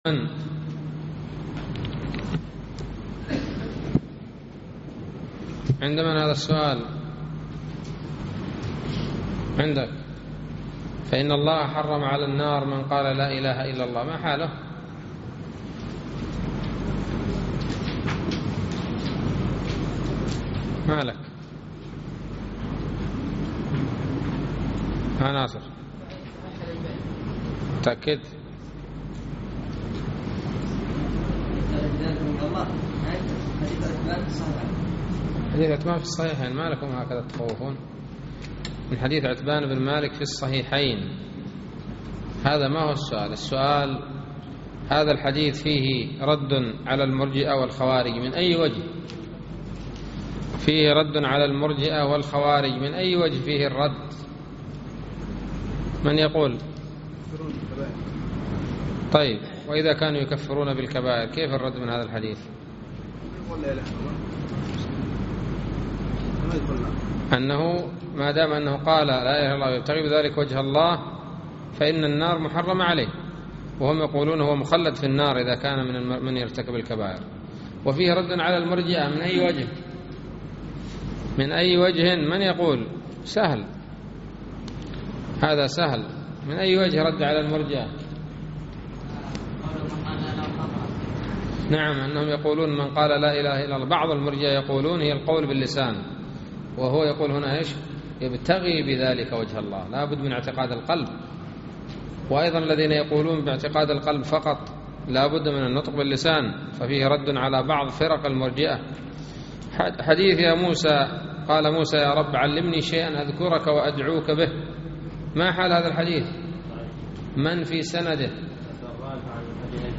الدرس الثامن باب من حقق التوحيد دخل الجنة بغير حساب نص المتن : وقول الله تعالى: }إِنَّ إِبْرَاهِيمَ كَانَ أُمَّةً قَانِتًا لِلّهِ حَنِيفًا وَلَمْ يَكُ مِنَ الْمُشْرِكِينَ{ ([1]).